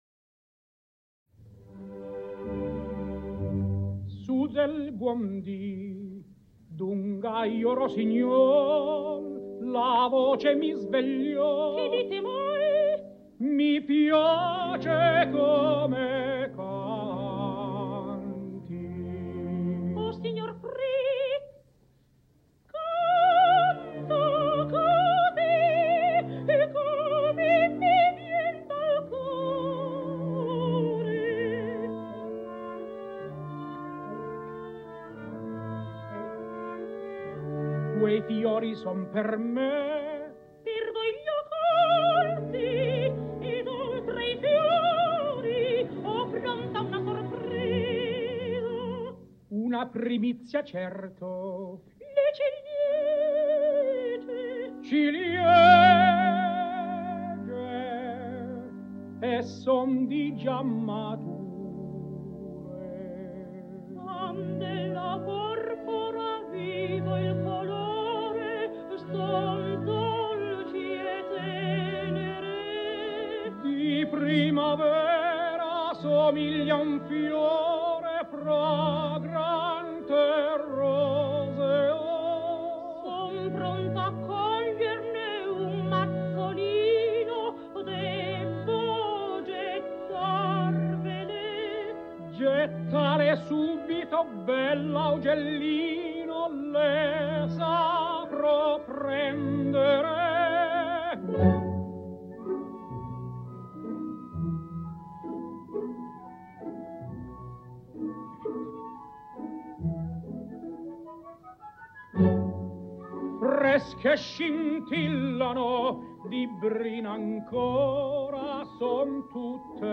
111 лет со дня рождения итальянской певицы (сопрано) Мафальды Фаверо (Mafalda Favero)